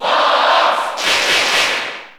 Category: Crowd cheers (SSBU) You cannot overwrite this file.
Fox_Cheer_Italian_SSB4_SSBU.ogg